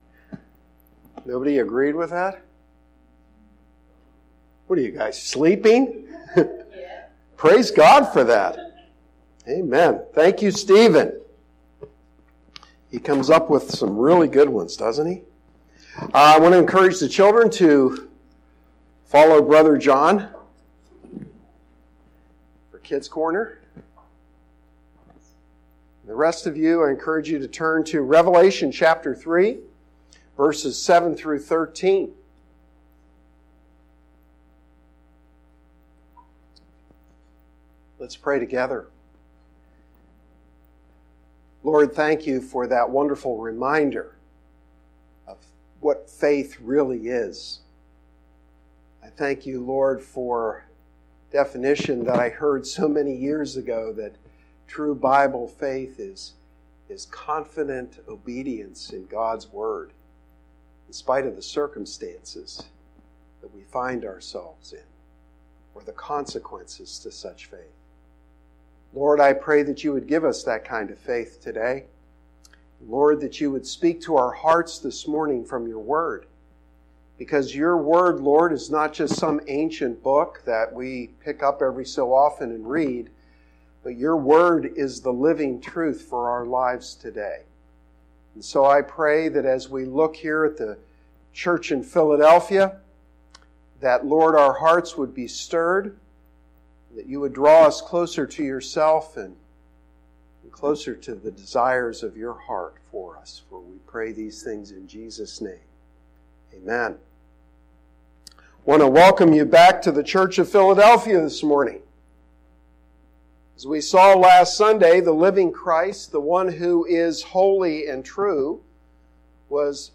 Sermon-5-26-19.mp3